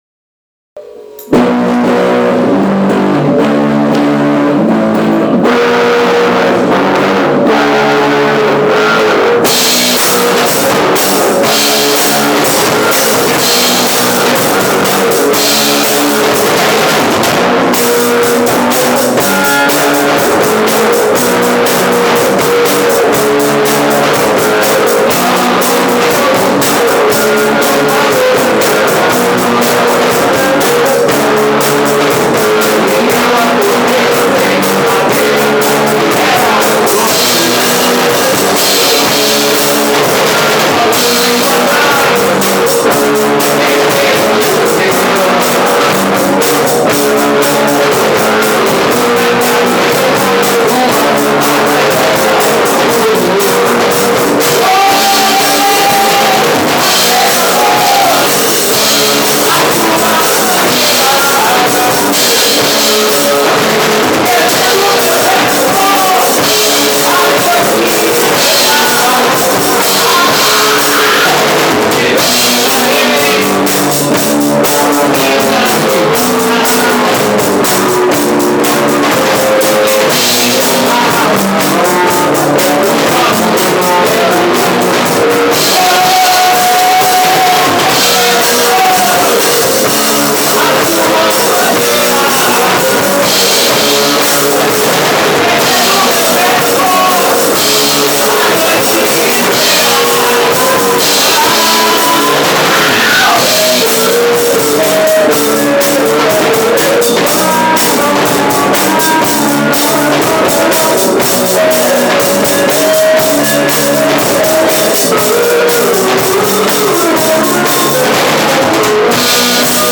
cover song.